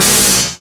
se_lazer00.wav